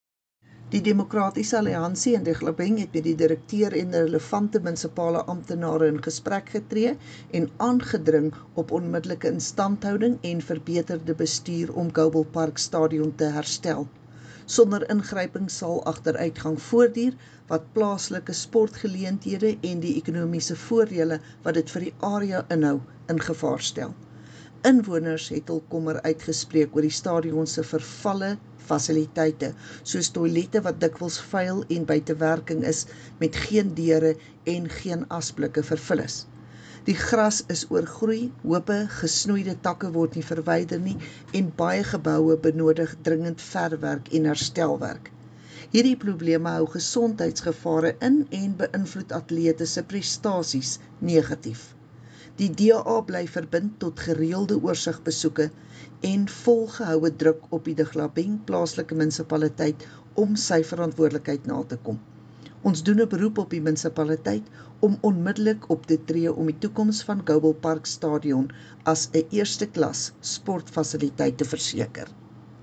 Afrikaans soundbites by Cllr Estie Senekal and